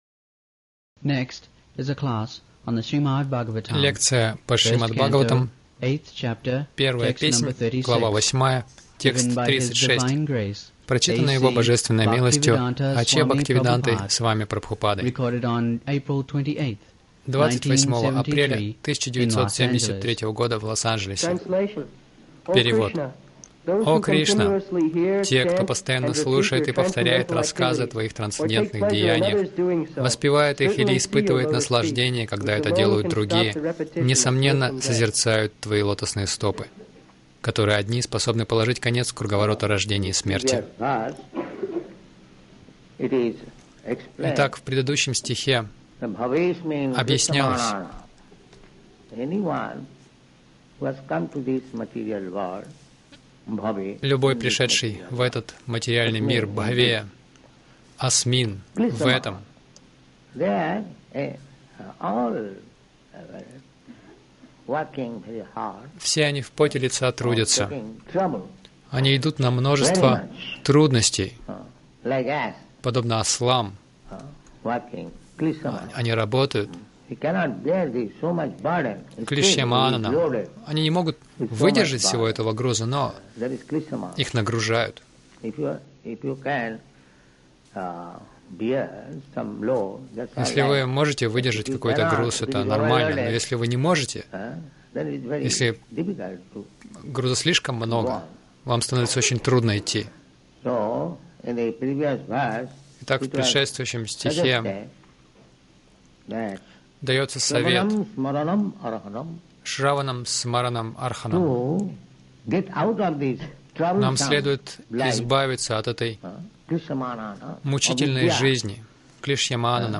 Милость Прабхупады Аудиолекции и книги 28.04.1973 Шримад Бхагаватам | Лос-Анджелес ШБ 01.08.36 — Просто слушайте о Кришне Загрузка...